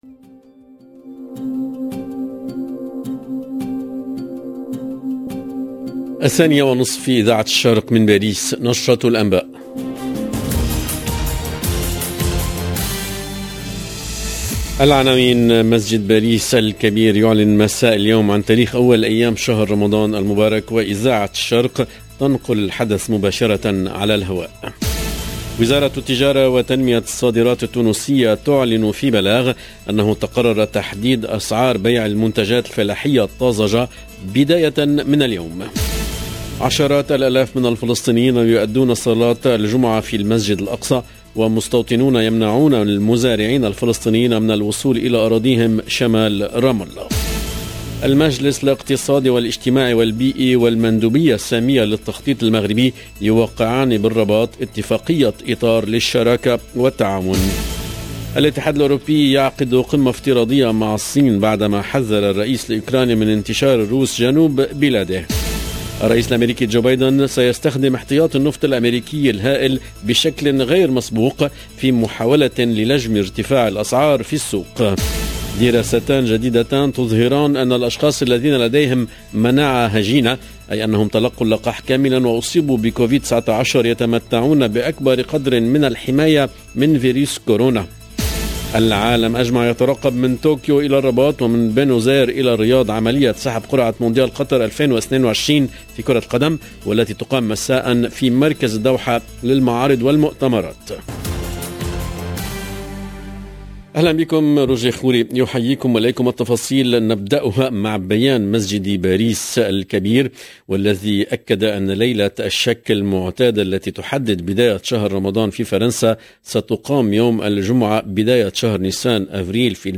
ramadan 1er avril 2022 - 18 min 15 sec LE JOURNAL EN ARABE DE LA MI-JOURNEE DU 1/04/22 LB JOURNAL EN LANGUE ARABE العناوين مسجد باريس الكبير يعلن مساء اليوم عن تاريخ اول ايام شهر رمضان المبارك وإذاعة الشرق تنقل الحدث مباشرة على الهواء....